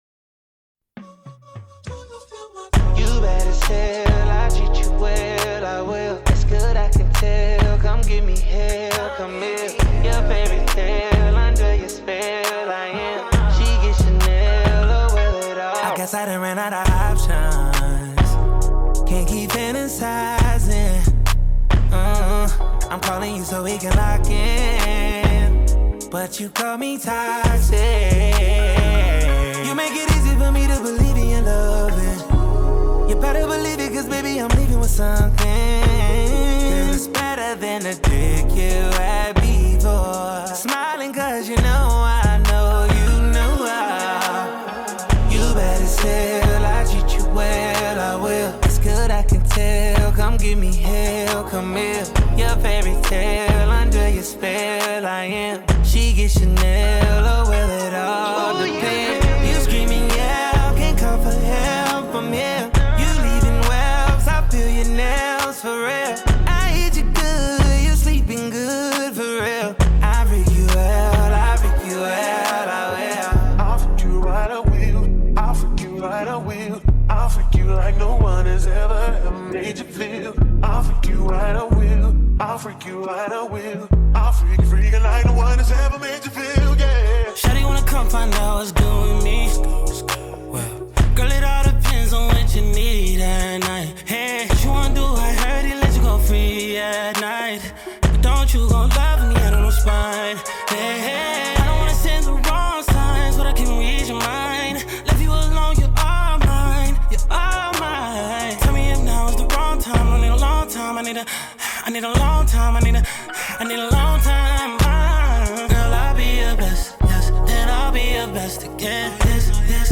* Track aus Video extrahiert.